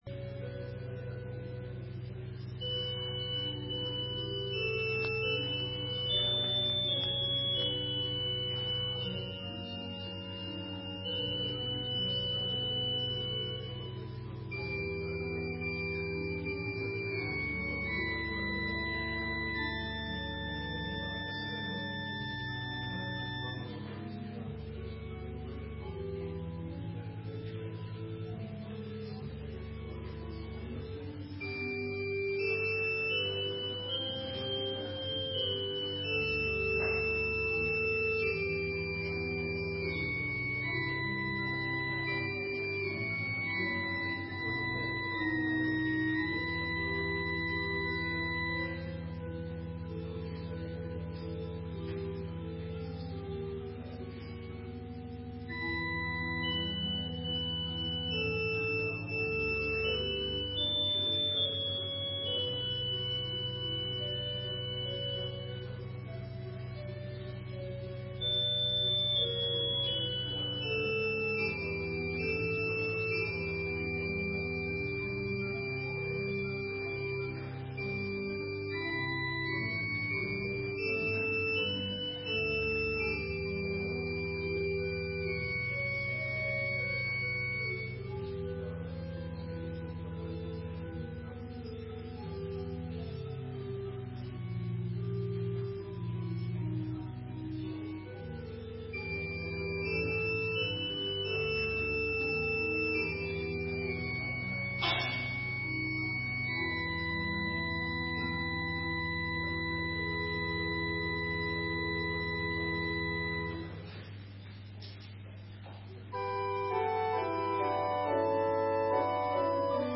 Opgenomen kerkdiensten